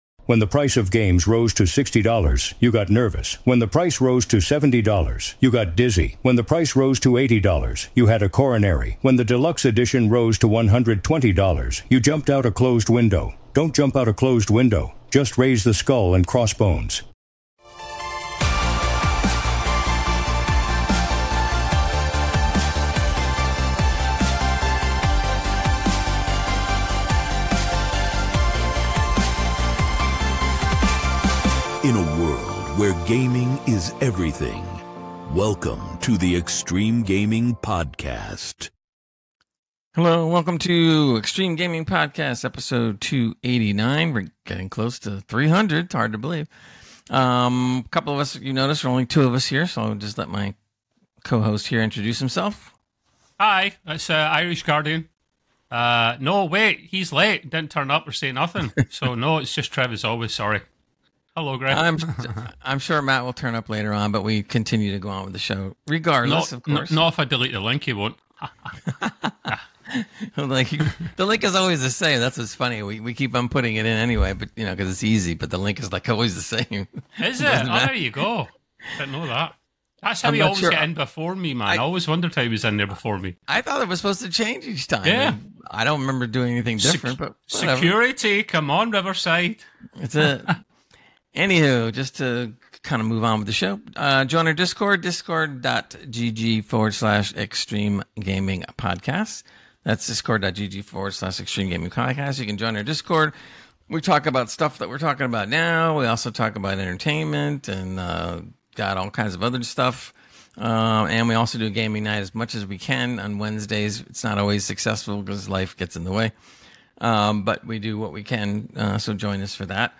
1 Alex Seropian, co-founder of Bungie (Live @ University of Chicago). 1:44:06